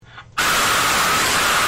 Scream Sound Effect Free Download
Scream